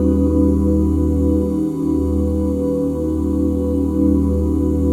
OOD#SHARP9.wav